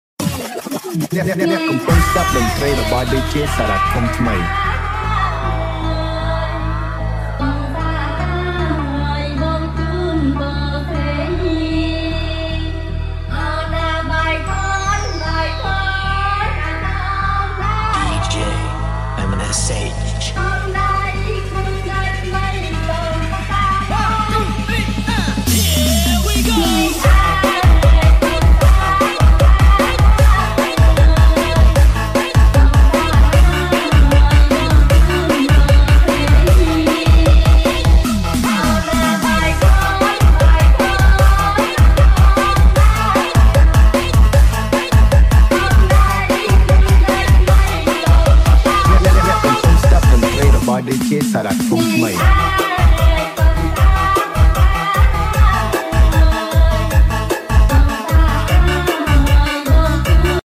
[Slowed & Reverb]